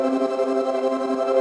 SaS_MovingPad05_170-C.wav